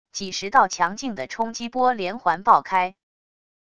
几十道强劲的冲击波连环爆开wav音频